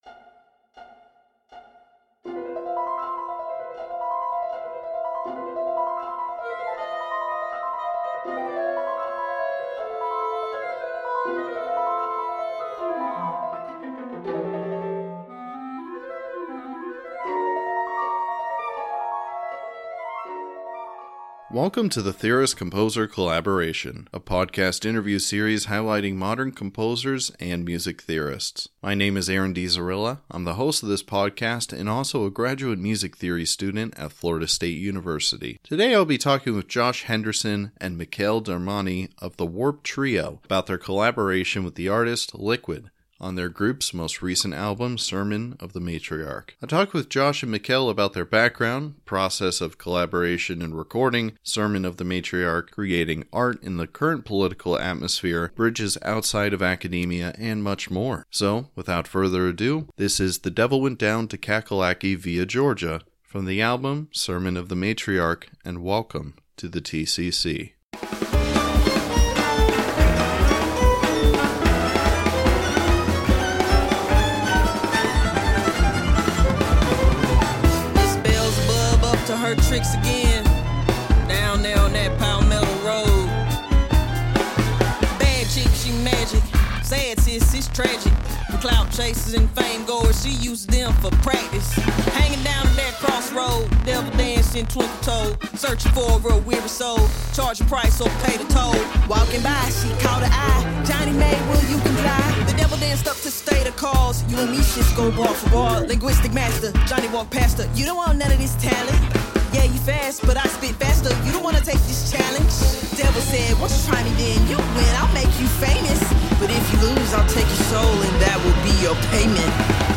The Vibration of Ancient Incarnations was performed by the University of Florida New Music Vocal Ensemble.